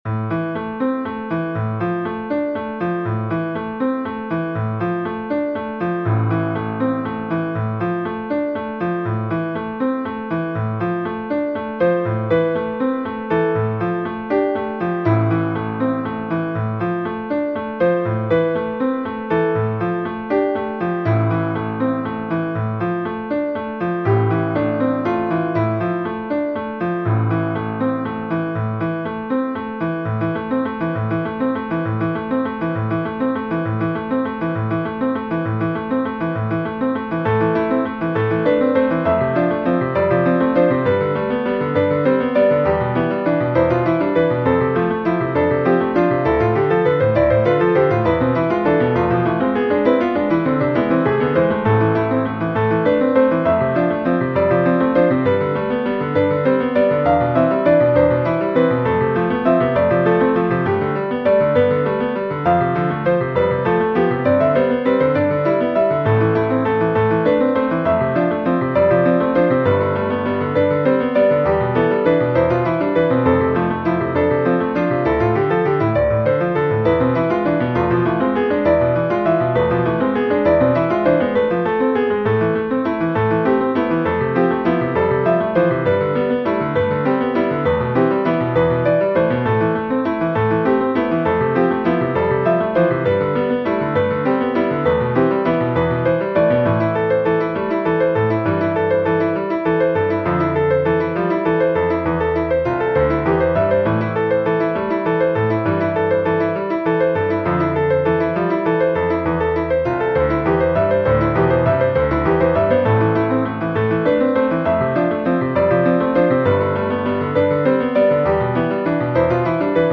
Here’s how a machine renders the piece: https
piano-song-in-a-minor.mp3